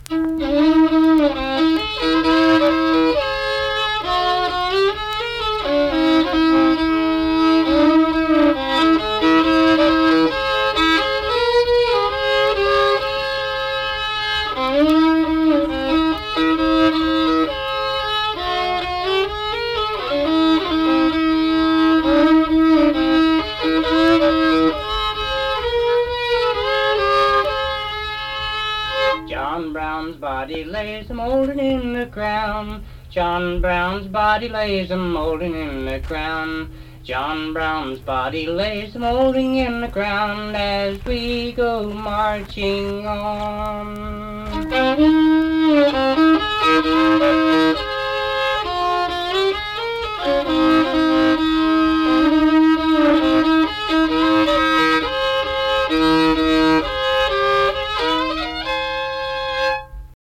Unaccompanied fiddle music and accompanied (guitar) vocal music performance
Instrumental Music, Political, National, and Historical Songs
Voice (sung), Fiddle
Braxton County (W. Va.)